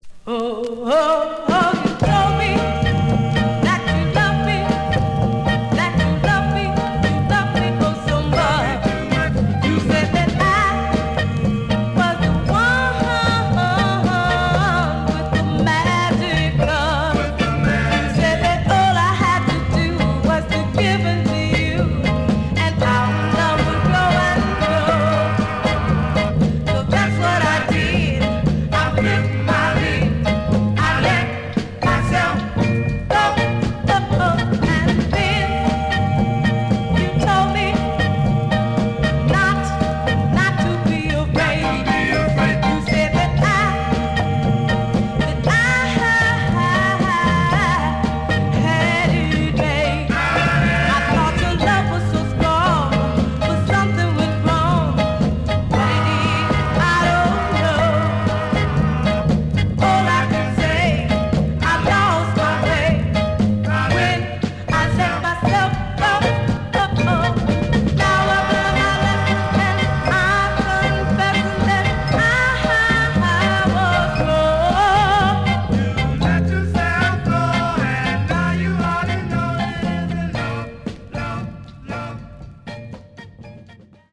Some surface noise/wear Stereo/mono Mono
Soul